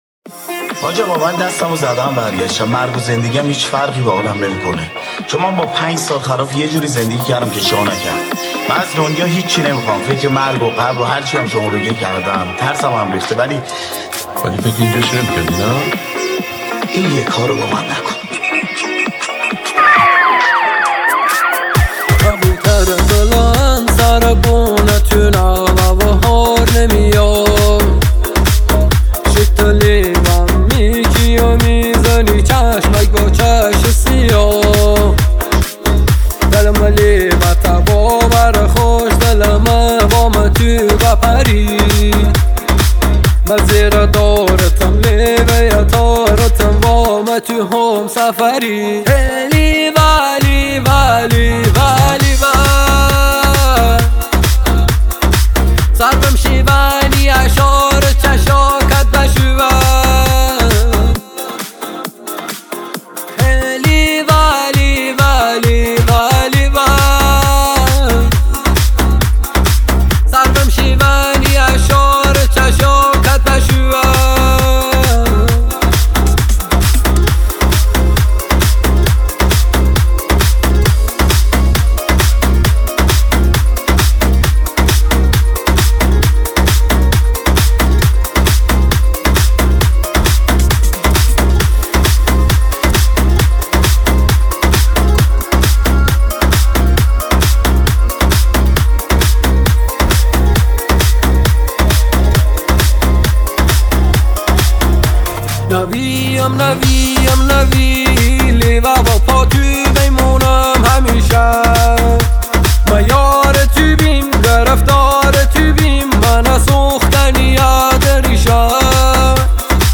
ترانه شاد و شنیدنی لری